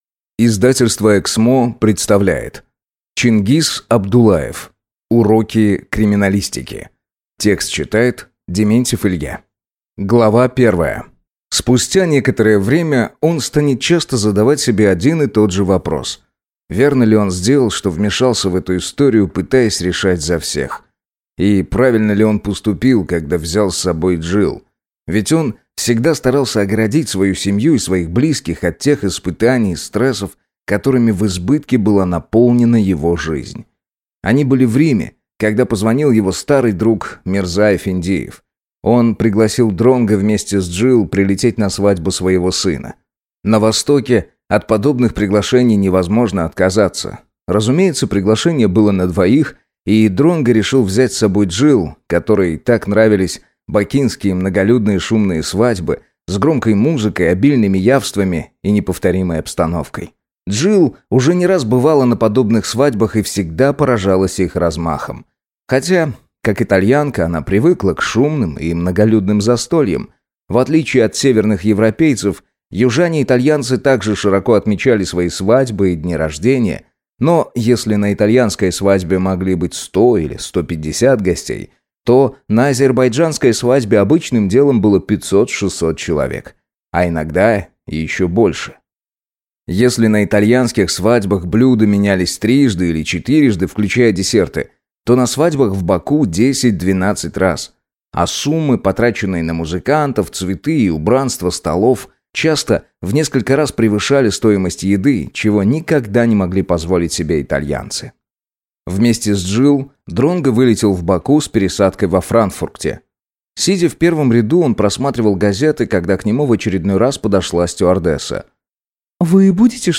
Аудиокнига Урок криминалистики | Библиотека аудиокниг